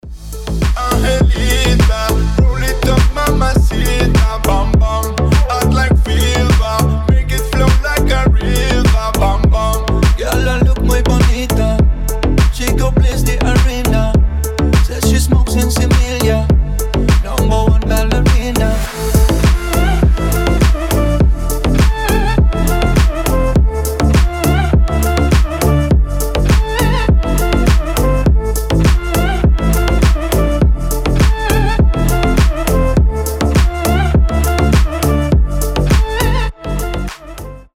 • Качество: 320, Stereo
deep house
ремиксы
Заводной испанский ремикс